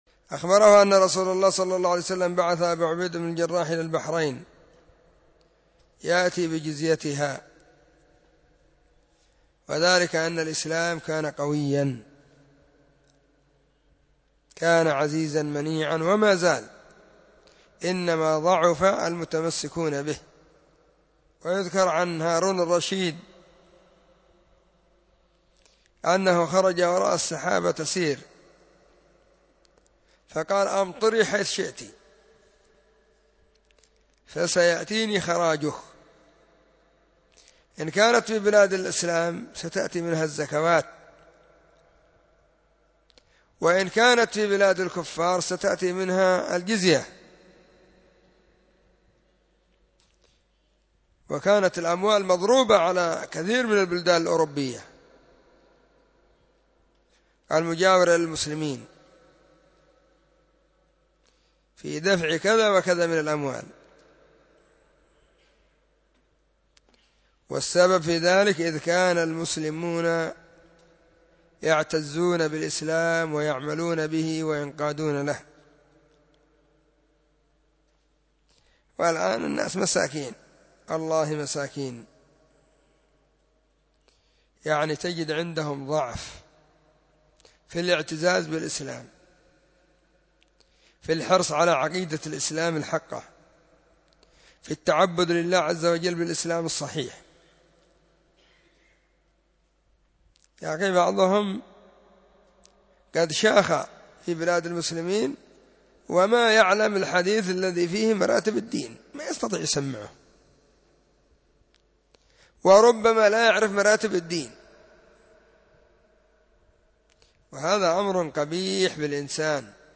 🔸🔹 سلسلة الفتاوى الصوتية المفردة 🔸🔹
📢 مسجد الصحابة – بالغيضة – المهرة، اليمن حرسها الله.